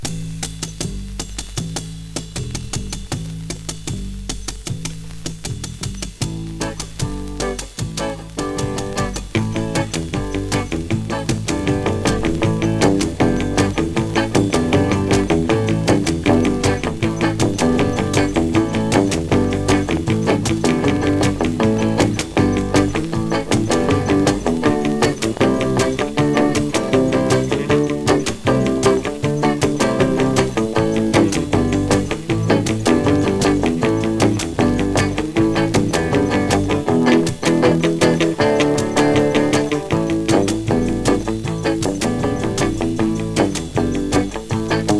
やわらかなイージーリスニング・ソフトロックンロールはリピート必至。